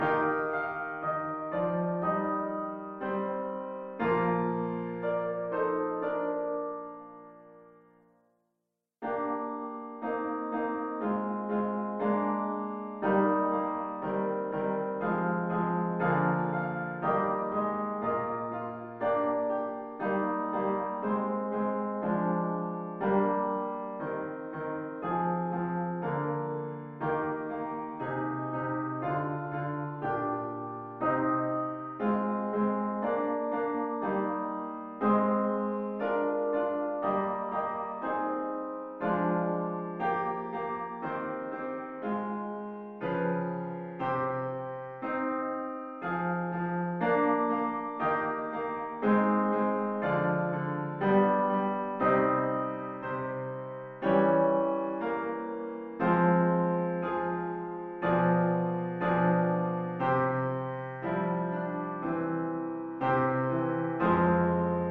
- Lotti (SATB)